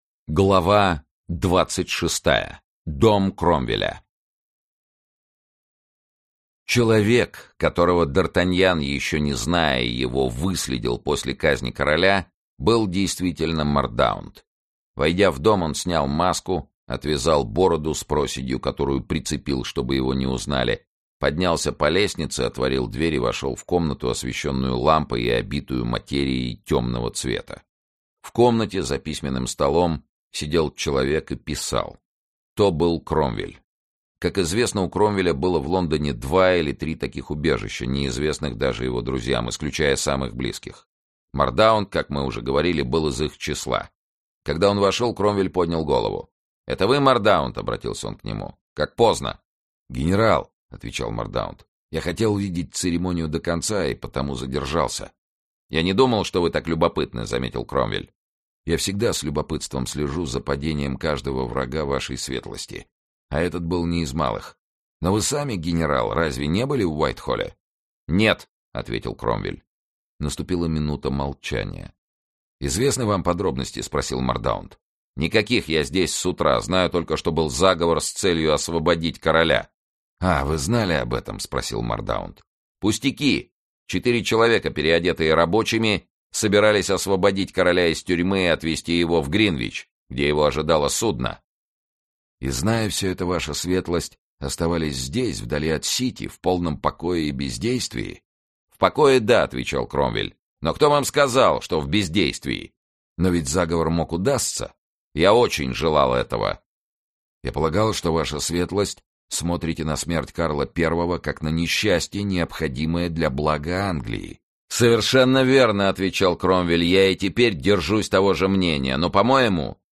Аудиокнига Двадцать лет спустя. Часть 4 | Библиотека аудиокниг
Часть 4 Автор Александр Дюма Читает аудиокнигу Сергей Чонишвили.